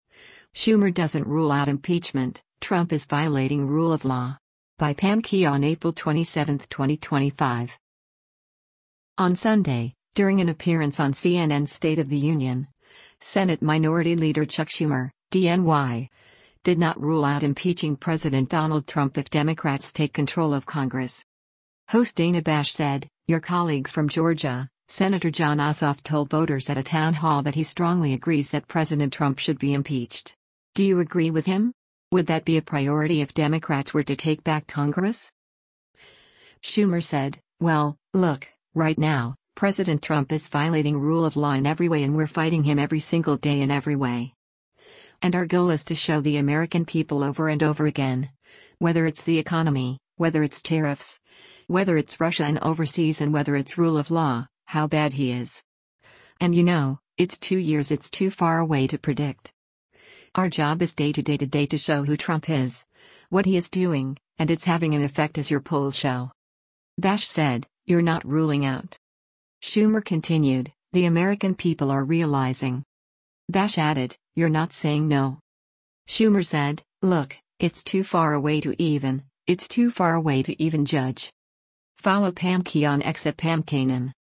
On Sunday, during an appearance on CNN’s “State of the Union,” Senate Minority Leader Chuck Schumer (D-NY) did not rule out impeaching President Donald Trump if Democrats take control of Congress.